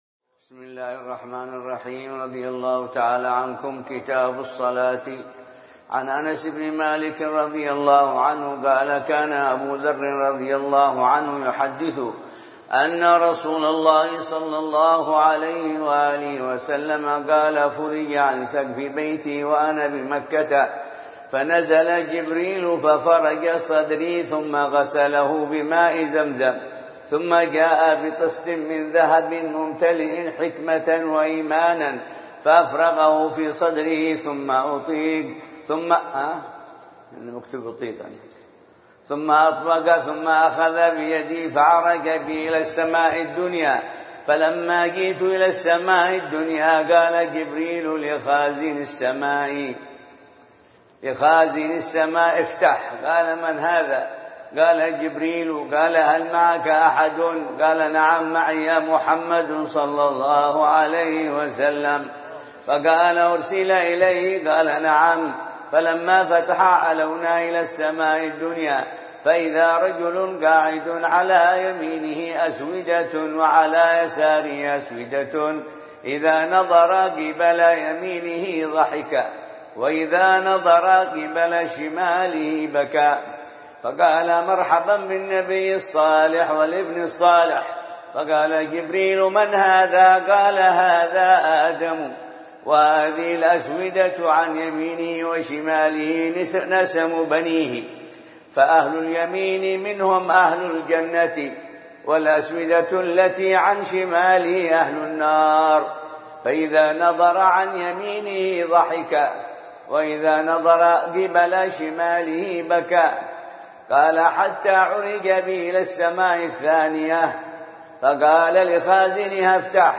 دروس الحديث